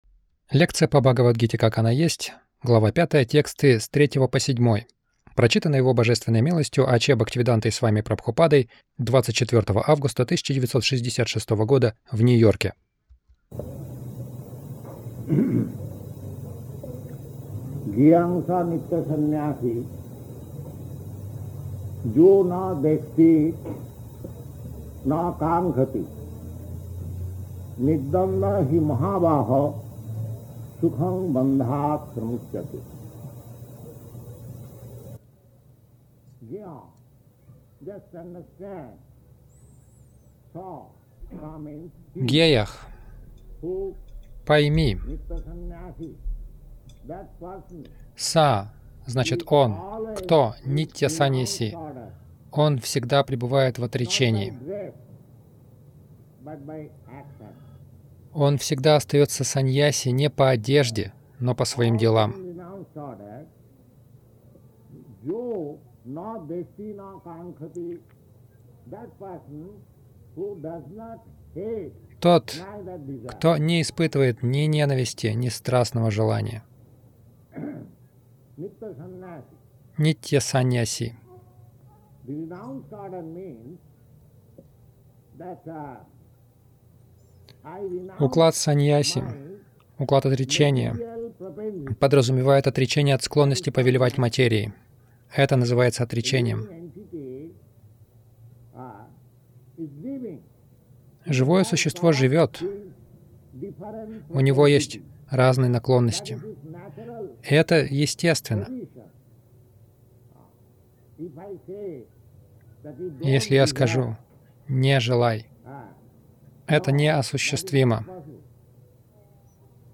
Милость Прабхупады Аудиолекции и книги 24.08.1966 Бхагавад Гита | Нью-Йорк БГ 05.03-07 — Истинная санньяса Загрузка... Скачать лекцию